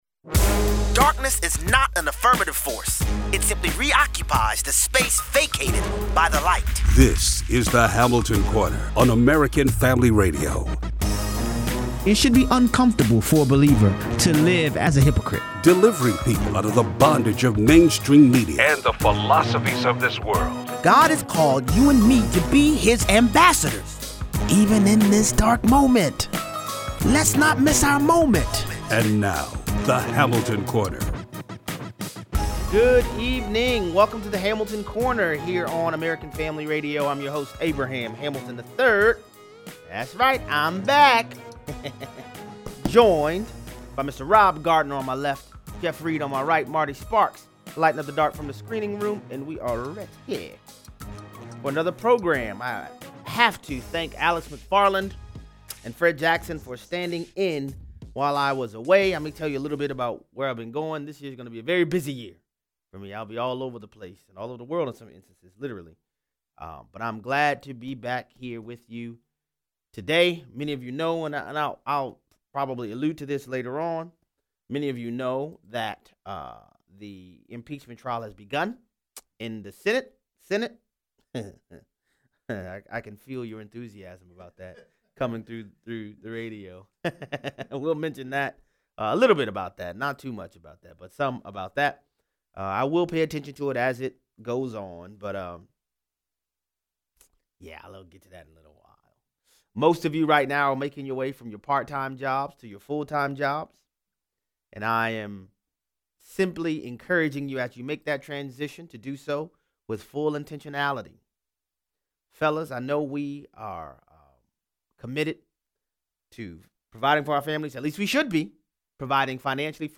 Christians continue to be persecuted in Nigeria. Callers weigh in.